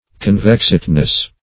Convexedness \Con*vex"ed*ness\, n.